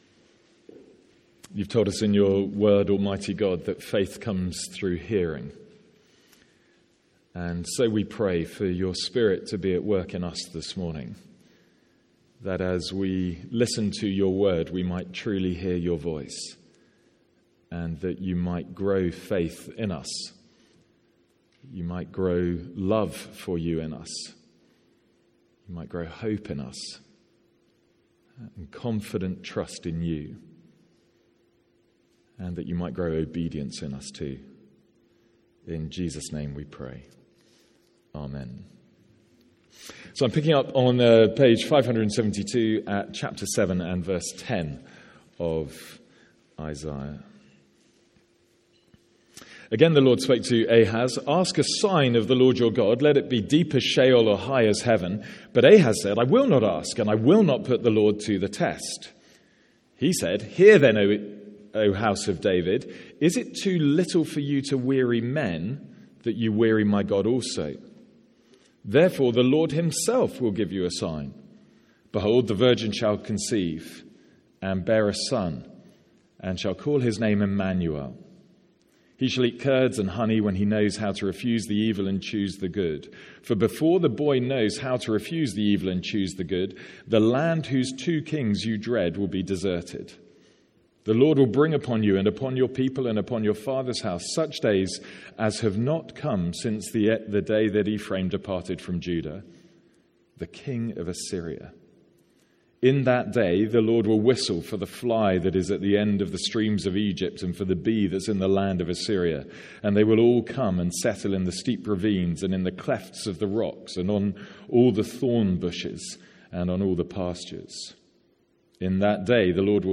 Sermons | St Andrews Free Church
From our morning service in Isaiah.